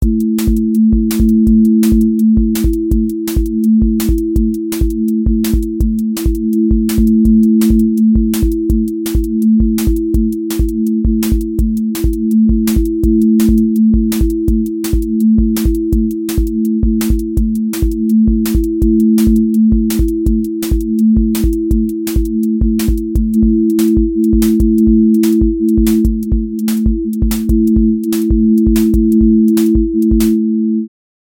QA Listening Test drum-and-bass Template: dnb_break_pressure
• voice_kick_808
• voice_snare_boom_bap
• voice_hat_rimshot
• voice_sub_pulse
• tone_brittle_edge